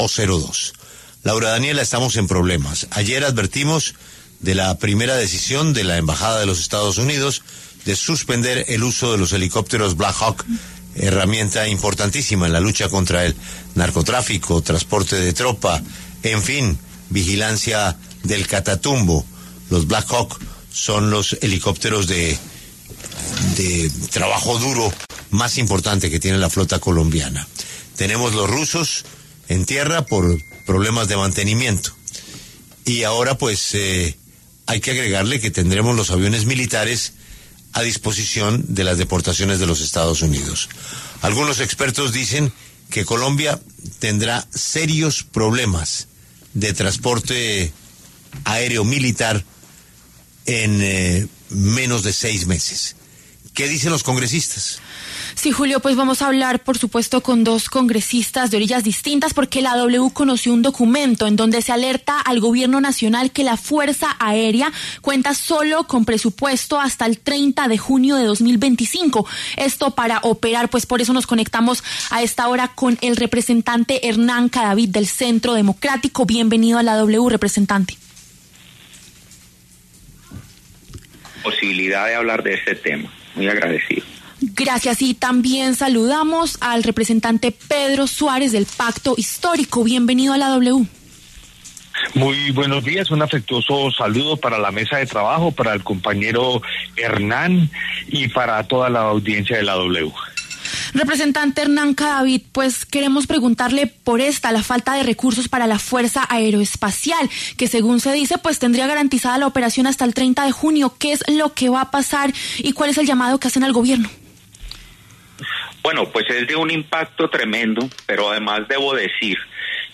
Los representantes Hernán Cadavid, del Centro Democrático, y Pedro Suárez, del Pacto Histórico, pasaron por los micrófonos de La W.